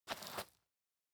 Illusion-UE5/Dirt Walk - 0002 - Audio - Dirt Walk 02.ogg at dafcf19ad4b296ecfc69cef996ed3dcee55cd68c
Footsteps implemented